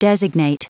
Prononciation du mot designate en anglais (fichier audio)
Prononciation du mot : designate